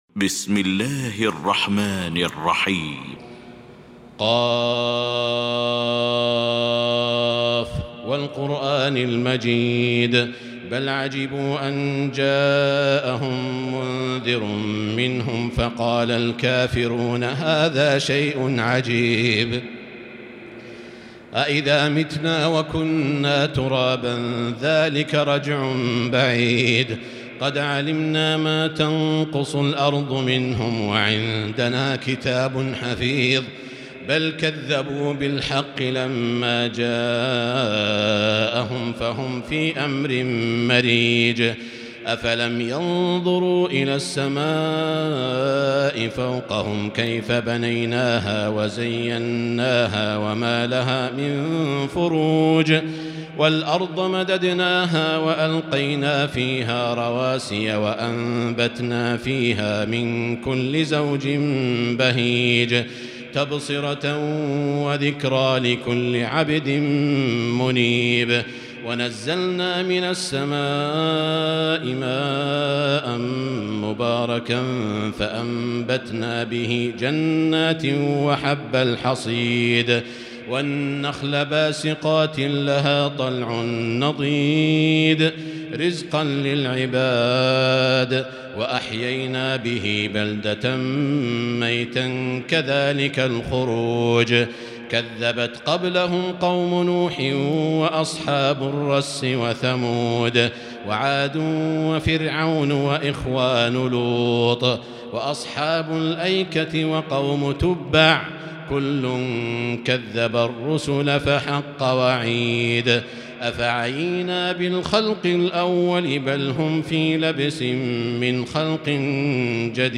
المكان: المسجد الحرام الشيخ: سعود الشريم سعود الشريم ق